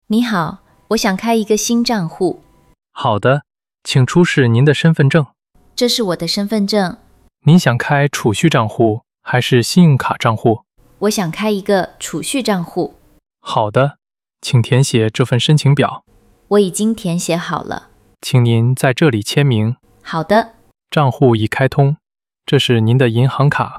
Hội thoại 1: Tại quầy giao dịch – Mở tài khoản